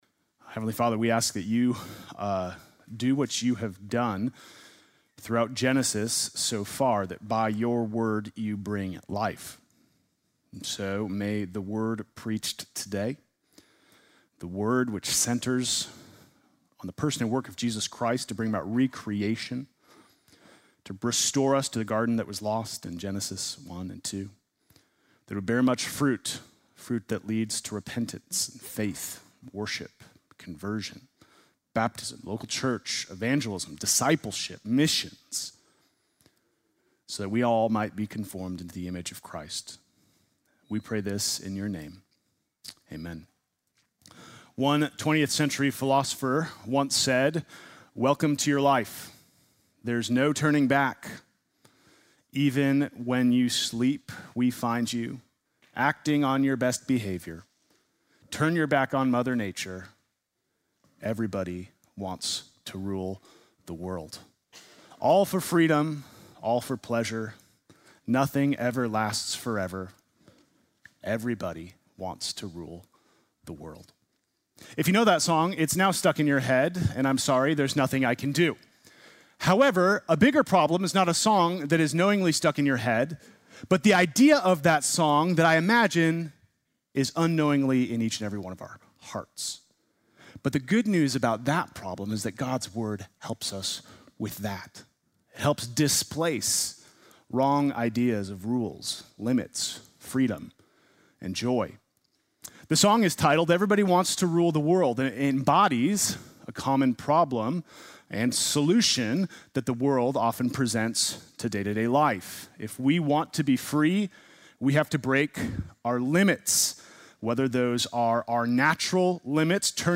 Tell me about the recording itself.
Sunday morning message August 17